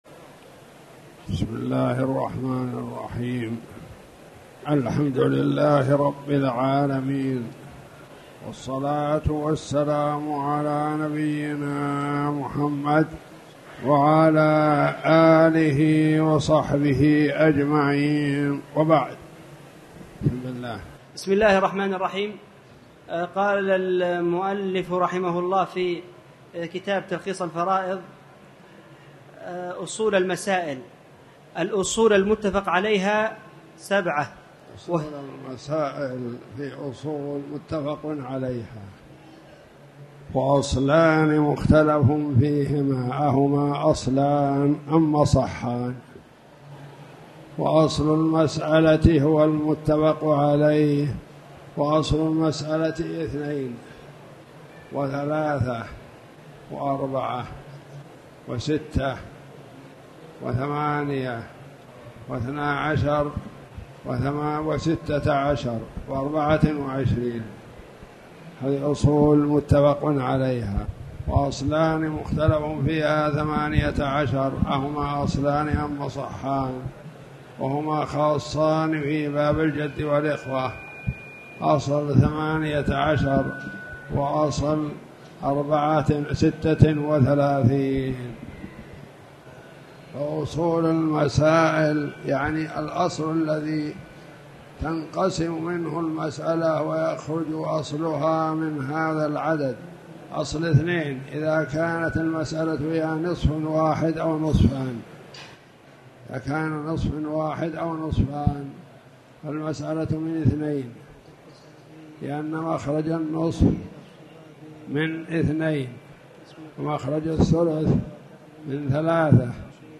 تاريخ النشر ١٦ ذو القعدة ١٤٣٨ هـ المكان: المسجد الحرام الشيخ